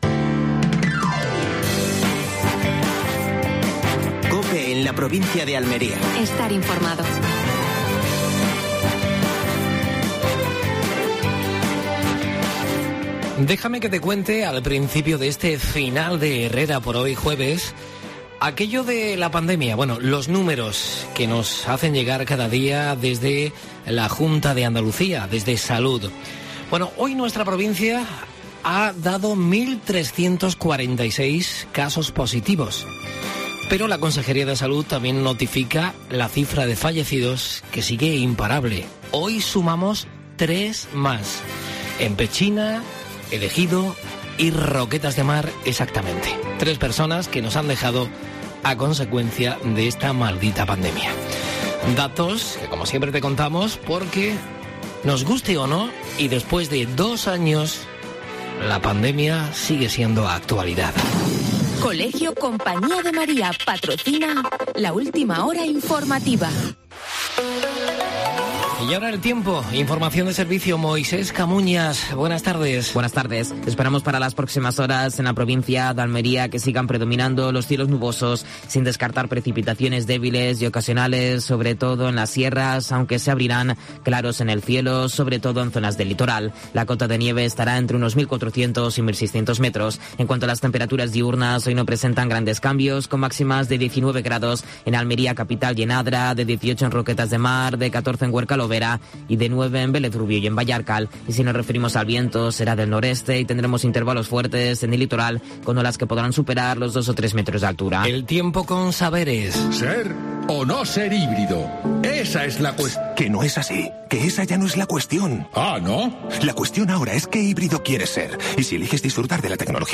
Previsión del tiempo. Estado de las carreteras y de la mar. Entrevista